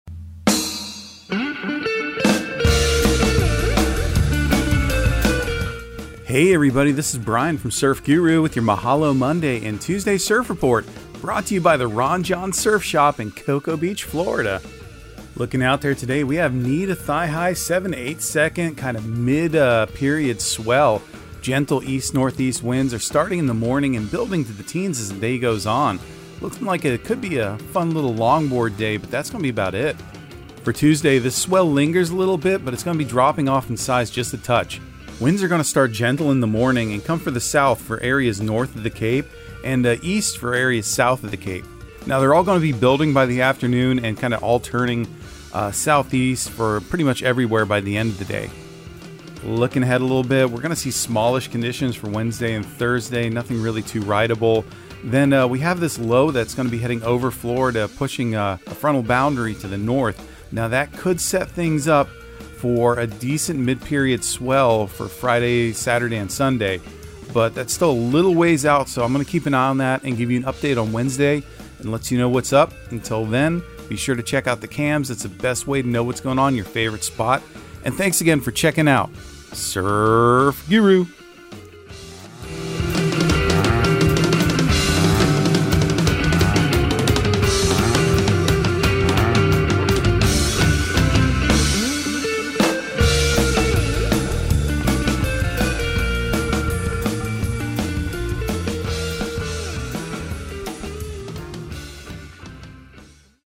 Surf Guru Surf Report and Forecast 05/15/2023 Audio surf report and surf forecast on May 15 for Central Florida and the Southeast.